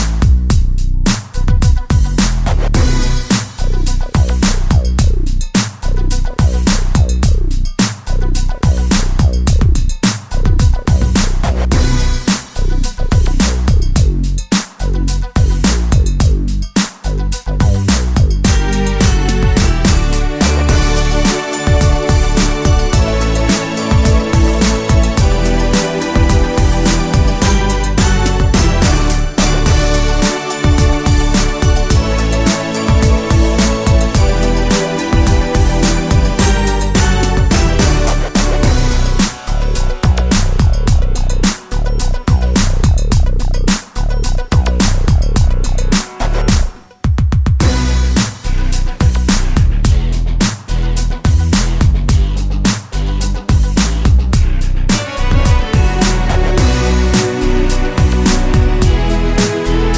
No Backing Vocals or Vocoder Pop (1990s) 4:22 Buy £1.50